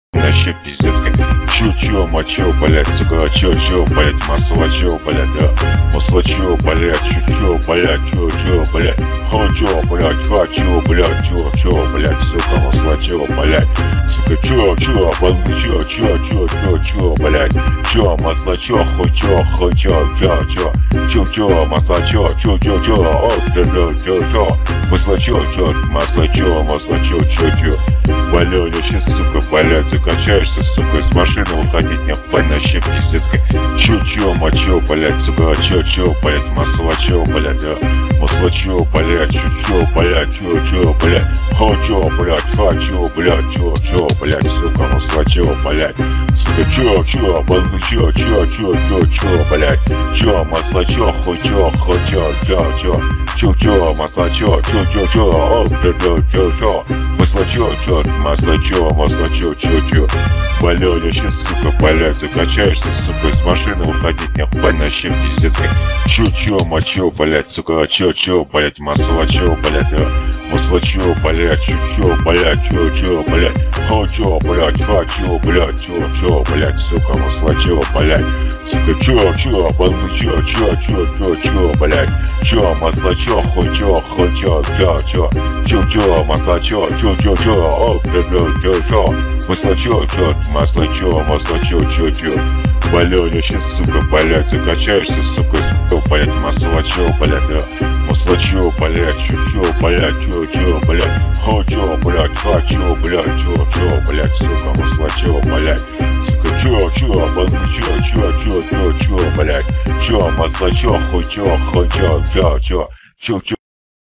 • Жанр: Хардрок